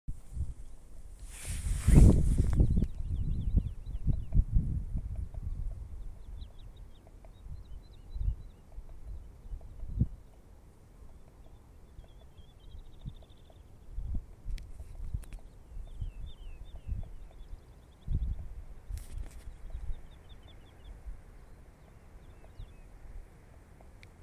Woodlark, Lullula arborea
Administratīvā teritorijaNīcas novads
StatusVoice, calls heard